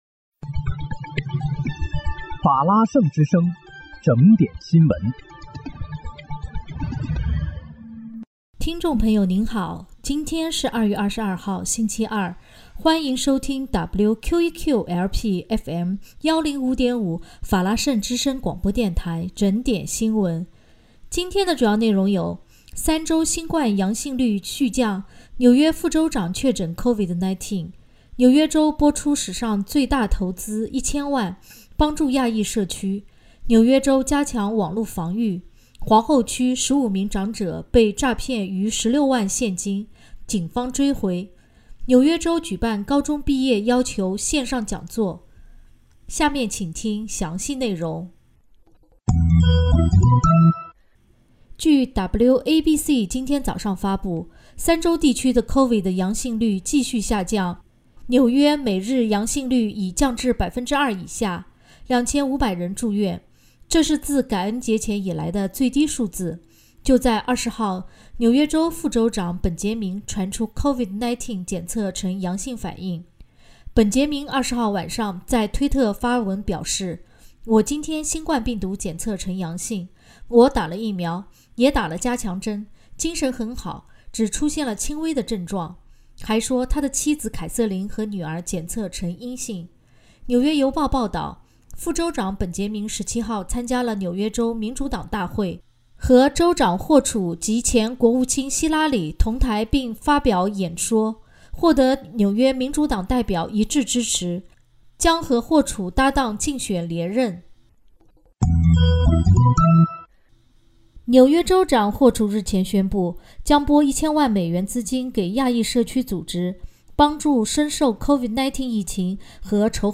2月22日（星期二）纽约整点新闻